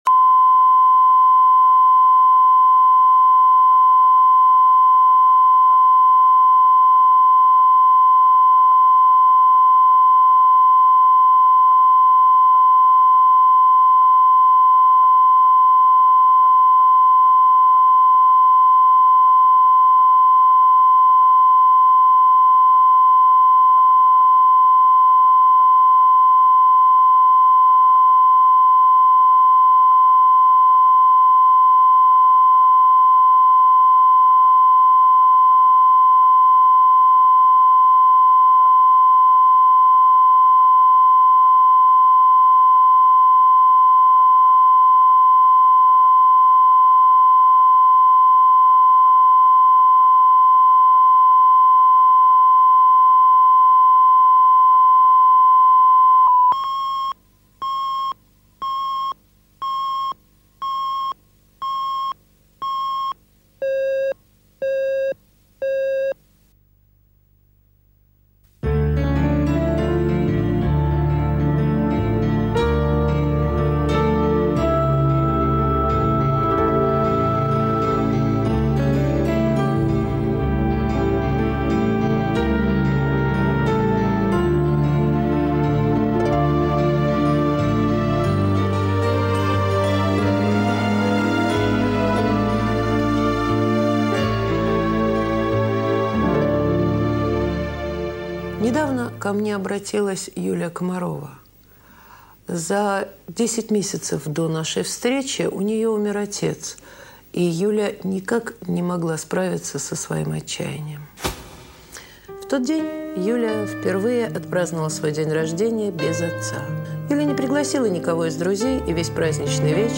Aудиокнига Коммуналка Автор Александр Левин. Прослушать и бесплатно скачать фрагмент аудиокниги